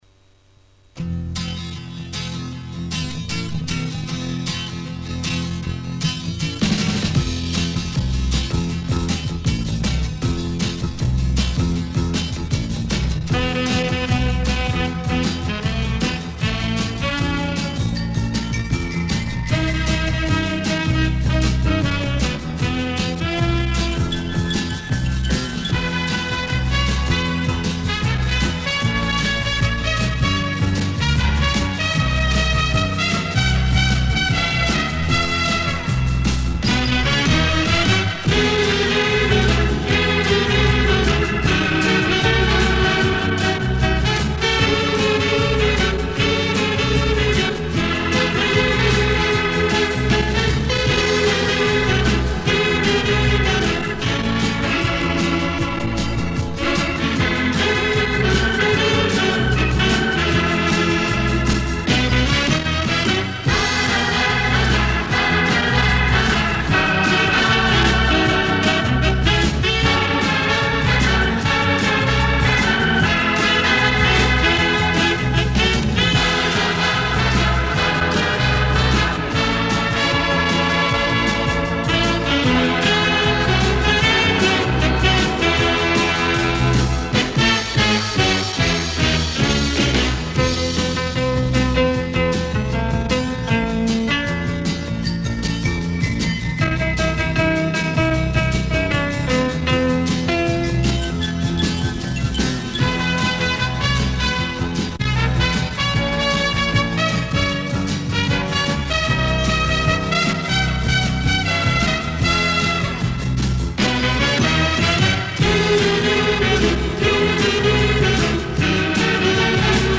старая лента 19.13